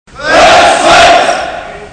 Young Latvian men of 18-25 are eligible to serve at least 1 year, and it is possible to hear marching songs in the morning floating over the walls of the compound.